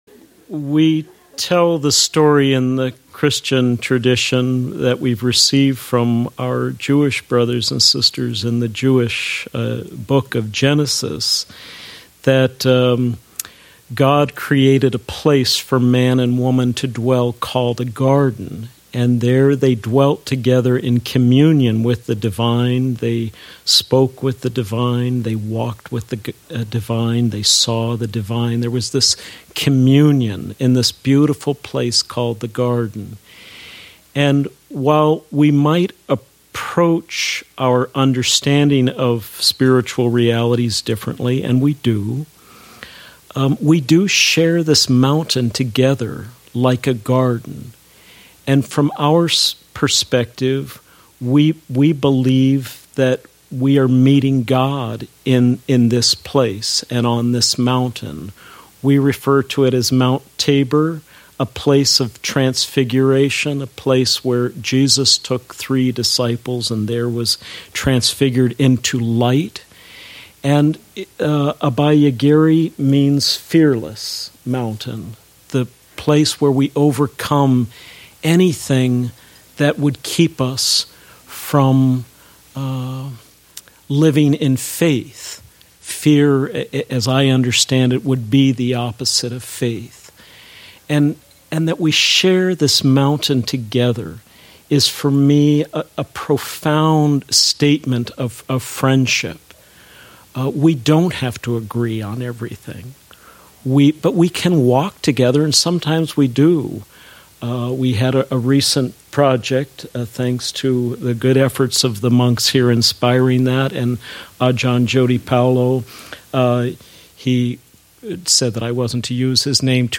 Reflection